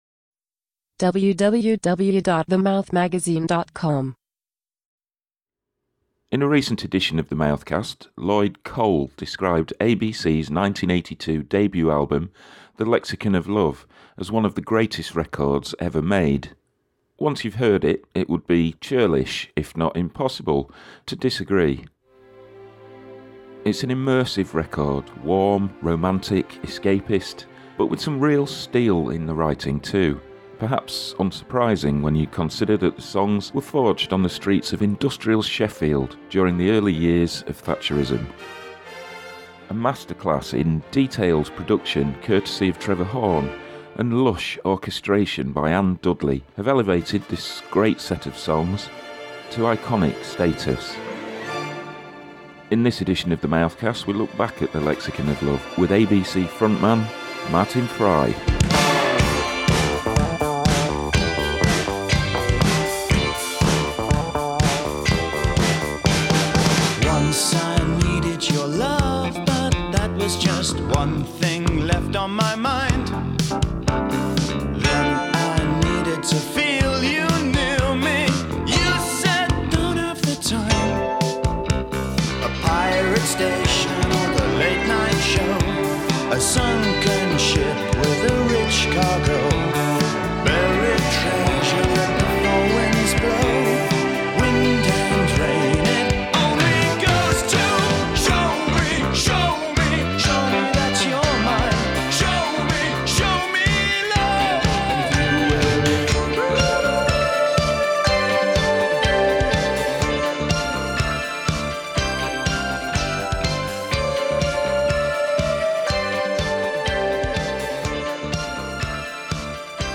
In this special 150th edition of The Mouthcast Fry speaks about the genesis of THE LEXICON OF LOVE and reflects on this fantastic album’s longstanding appeal…